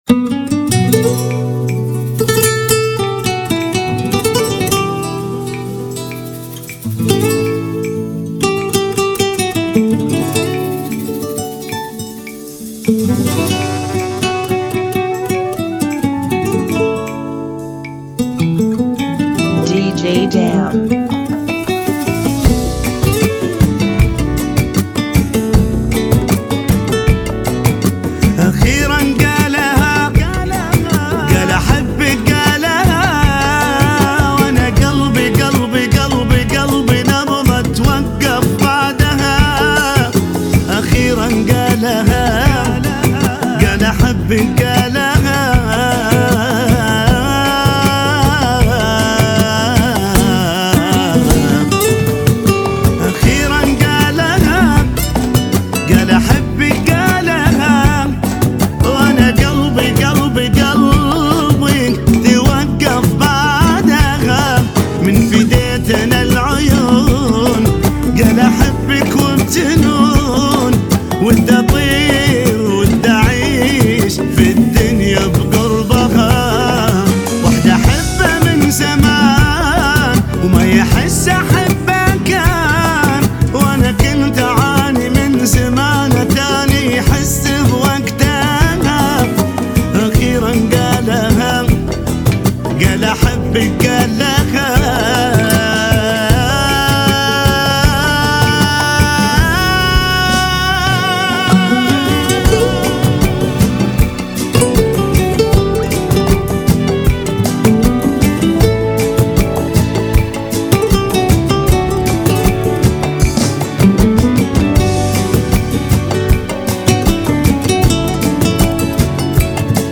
156 BPM
Genre: Salsa Remix